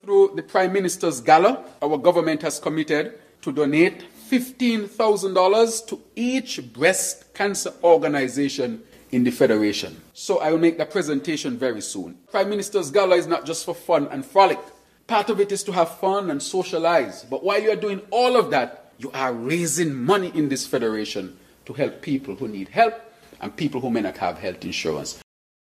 During a sitting of the National Assembly on February 12th, Prime Minister and Federal Minister of Health, the Hon. Dr. Terrance Drew, made this announcement: